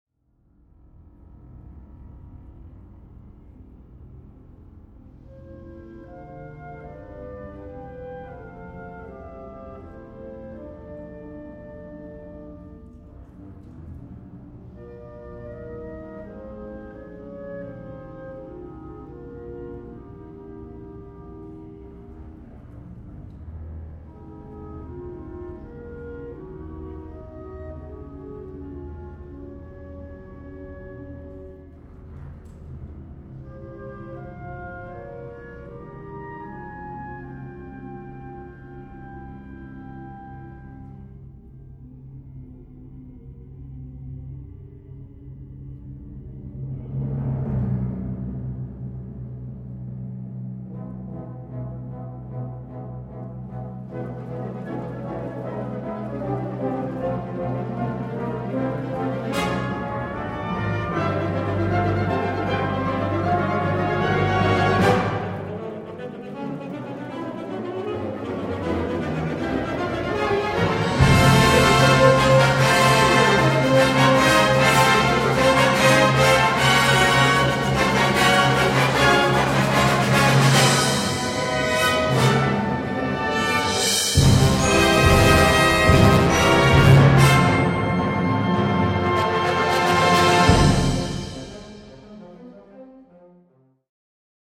Concert Band Version
Key: F lydian mode